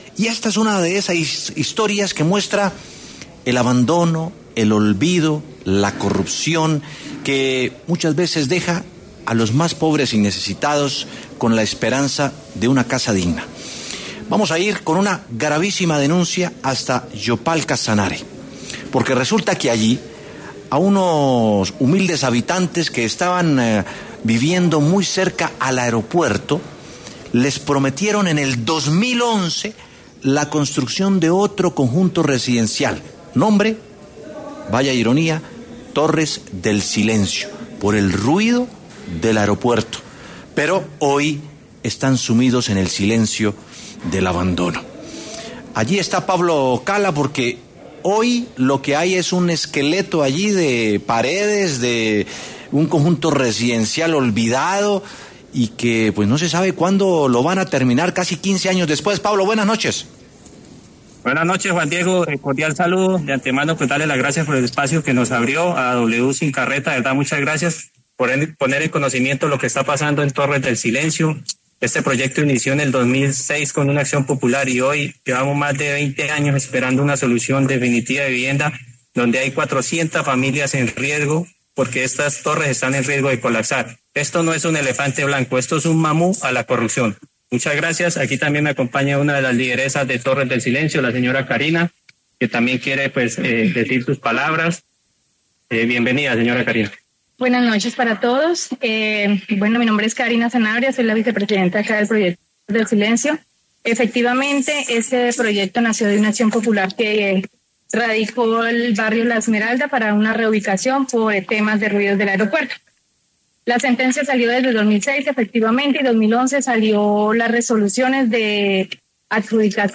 En W Sin Carreta hablamos con Marco Tulio Ruiz, el alcalde de Yopal, quien respondió sobre esta denuncia, que seguirá en desarrollo para la verificación de su cumplimiento.